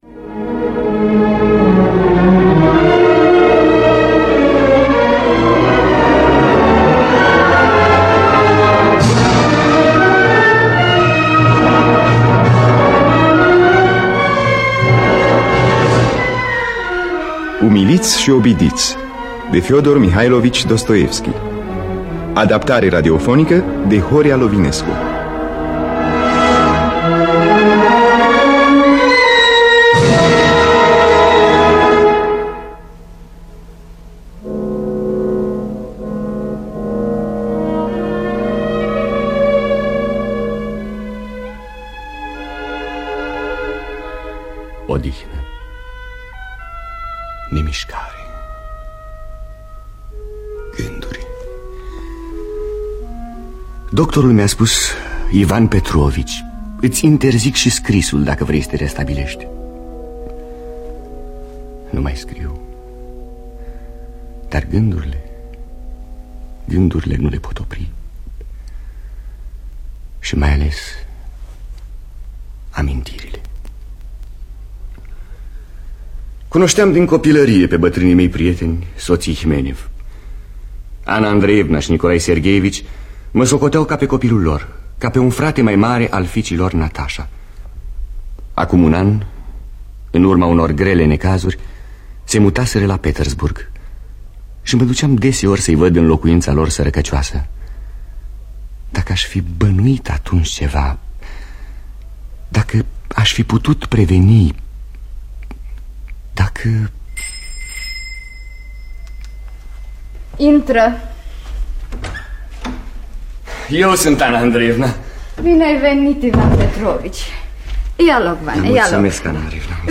Dramatizarea şi adaptarea radiofonică de Horia Lovinescu.